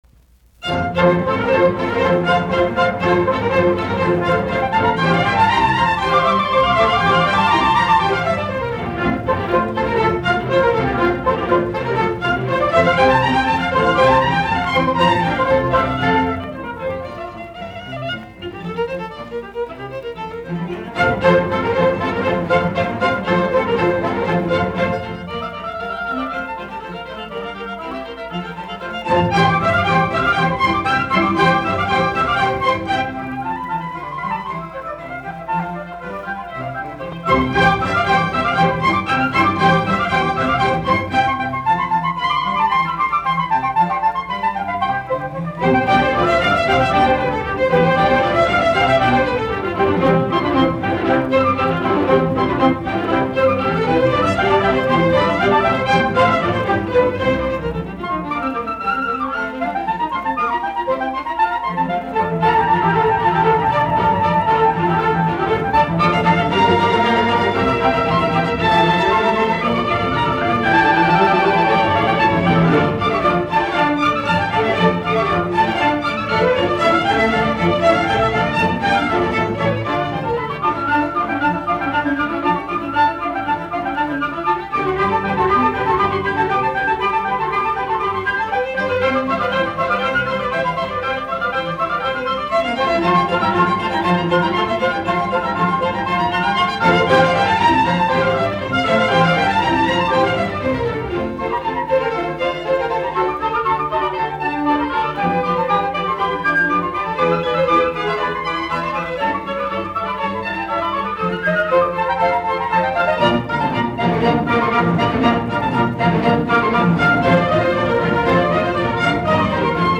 BWV1047, F-duuri